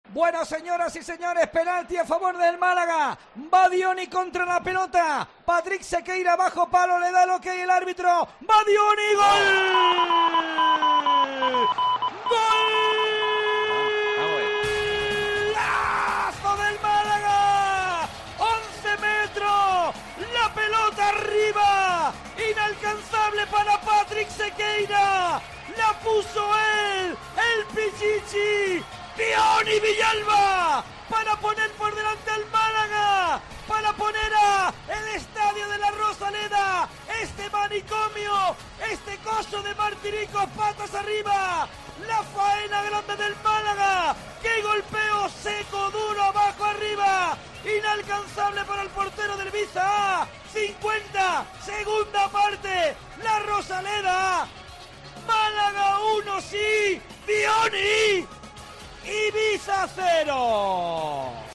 Así te hemos narrado el gol de la victoria del Málaga ante el Ibiza (1-0)